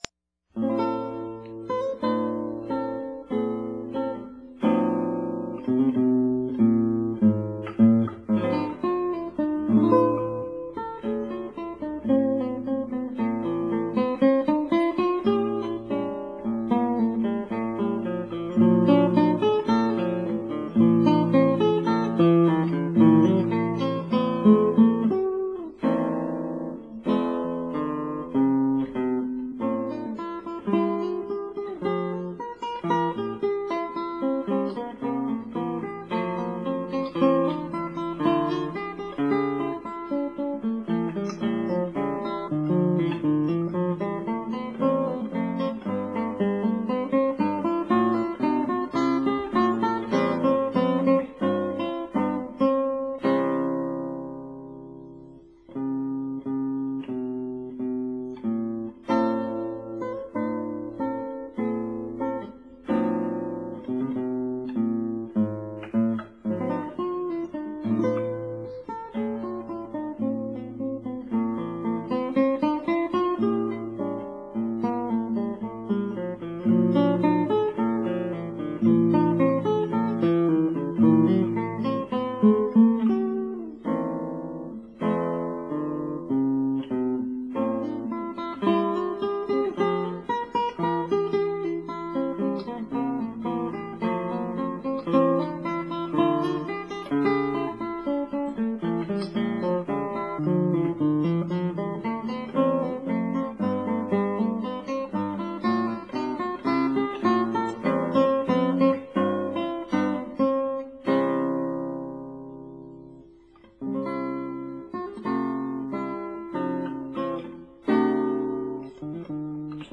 guitar Date